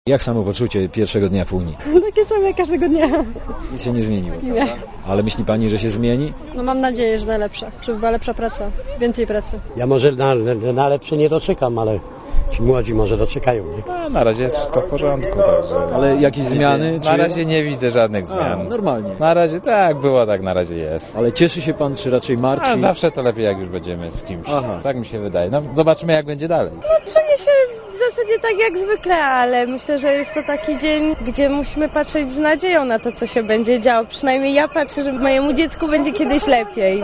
Z mieszkańcami Krakowa rozmawia Reporter Radia ZET.